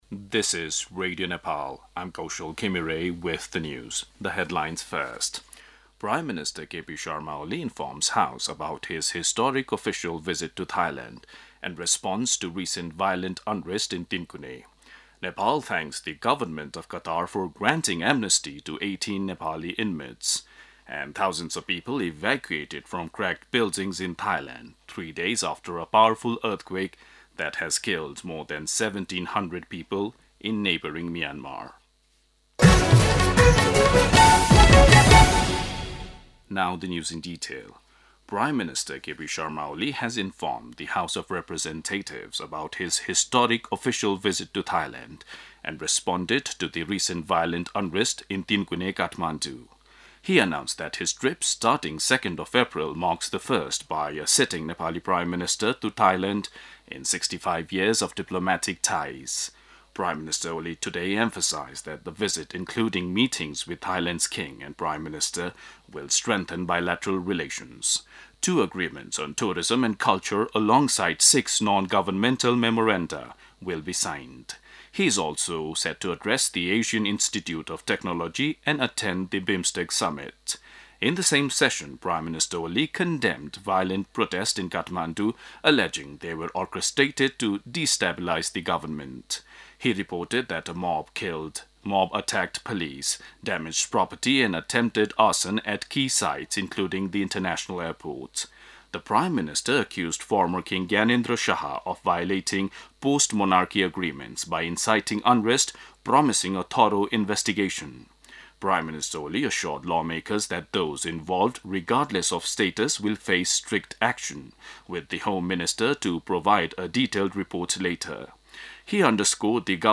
दिउँसो २ बजेको अङ्ग्रेजी समाचार : १८ चैत , २०८१